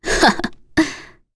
Maria-Vox-Laugh1.wav